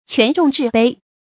权重秩卑 quán zhòng zhì bēi
权重秩卑发音